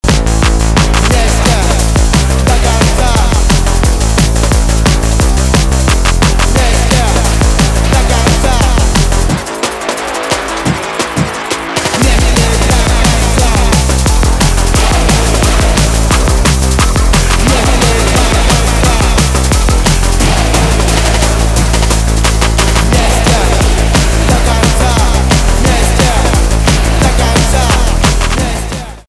электроника , битовые , басы , качающие